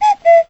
player_join.wav